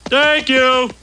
1 channel
thanku.mp3